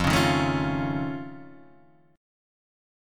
F+7 chord